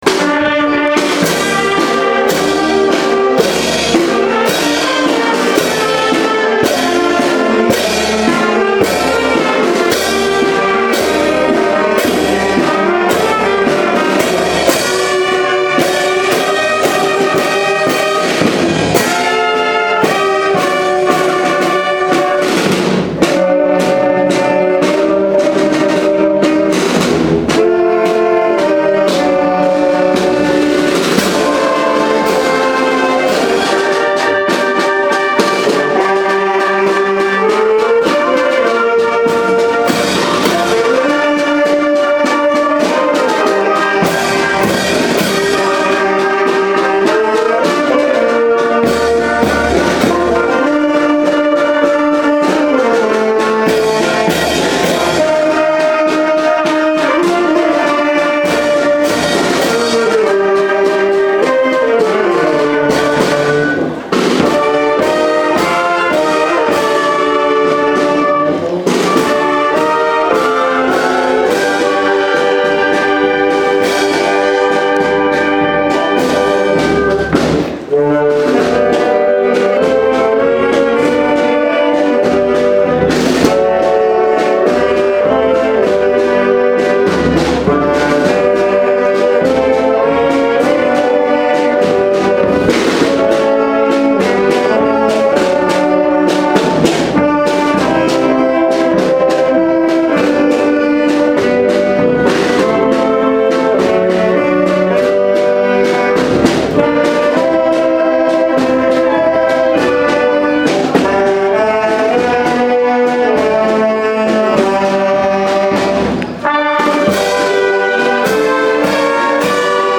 El pasado 26 de abril tuvo lugar una celebración eucarística en el centro social del barrio Tirol Camilleri en honor a San Marcos Evangelista, patrón del barrio.
Posteriormente tuvo lugar la procesión que recorrió algunas calles del barrio, acompañada por la  banda municipal de música de Totana.